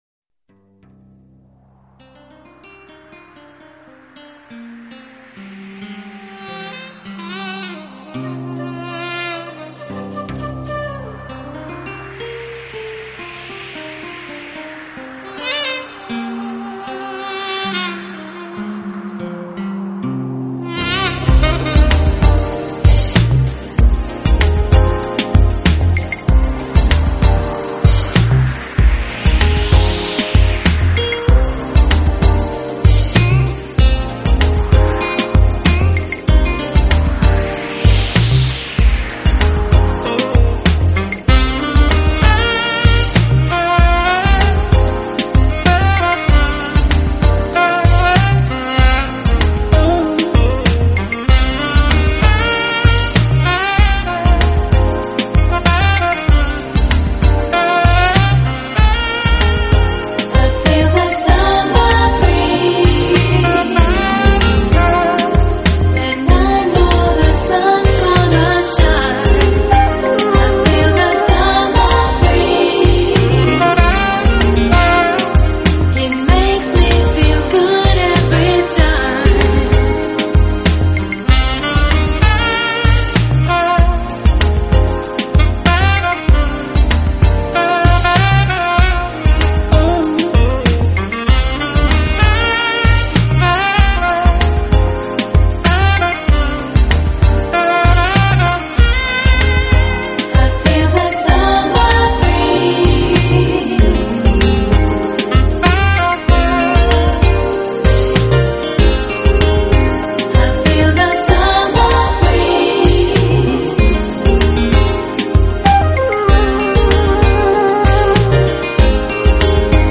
录制唱片，由于善于把握时尚元素，将Smooth Jazz与电子、舞曲风格完美结合，
旋律轻柔流畅，器乐创新搭配，节奏舒缓时尚，魅力女声
如同和煦清爽的凉风与清凉透心的泉水流淌全身，令人舒适之极。